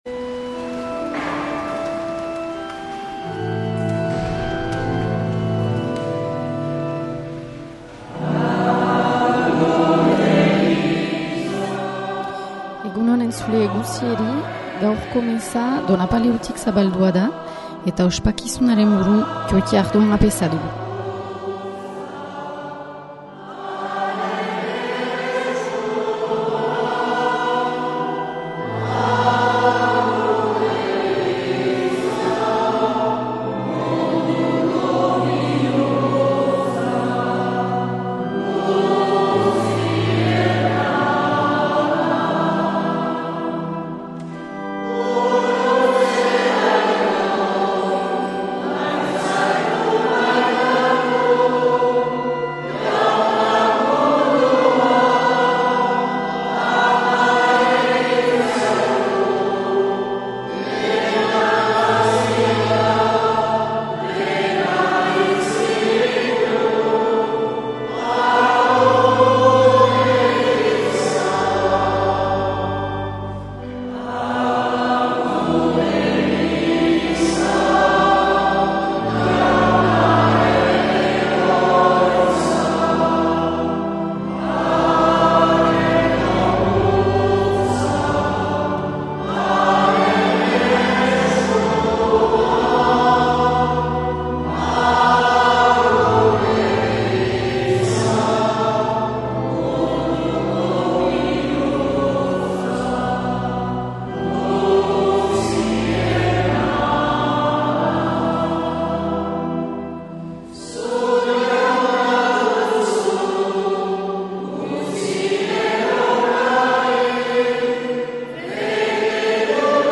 Accueil \ Emissions \ Vie de l’Eglise \ Célébrer \ Igandetako Mezak Euskal irratietan \ 2025-09-08 Urteko 23.